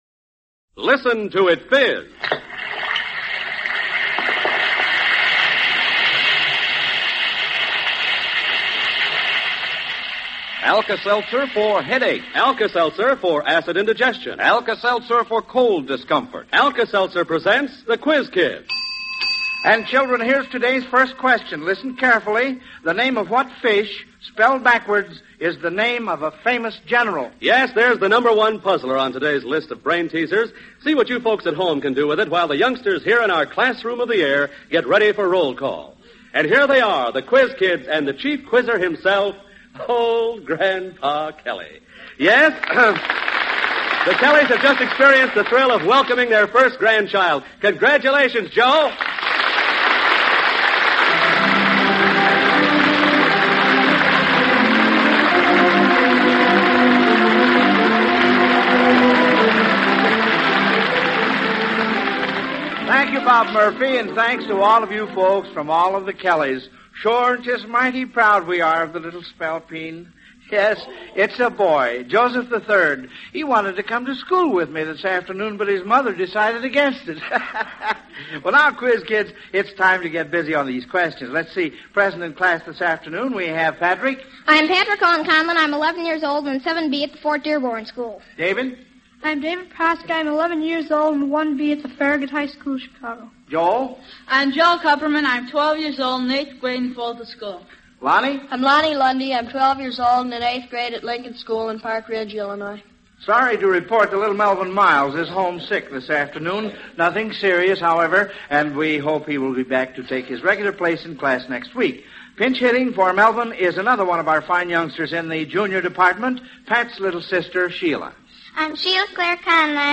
The Quiz Kids Radio Program